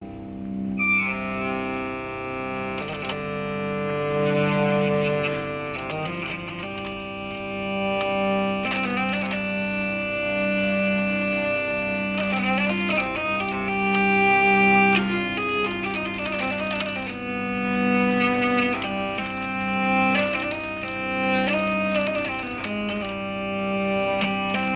Audición de diferentes sonidos de la familia de cuerda frotada.
Zanfona o Zanfoña